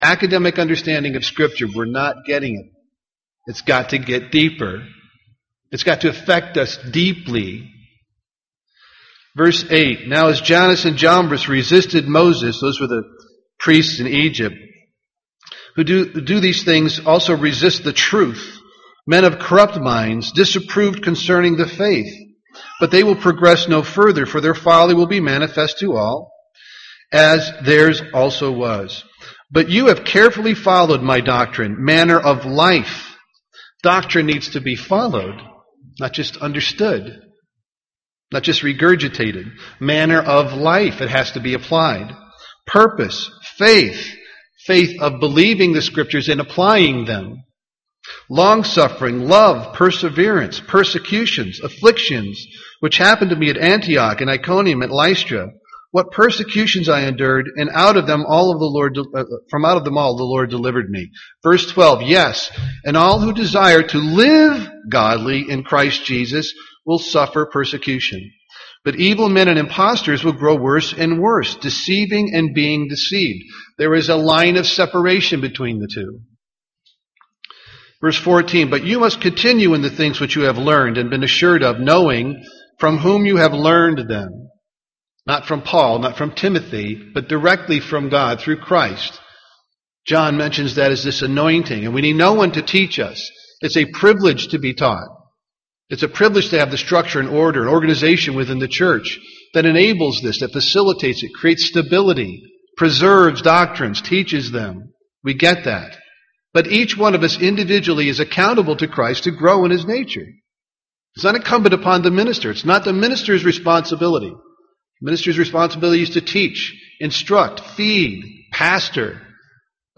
Given in Twin Cities, MN
UCG Sermon Studying the bible?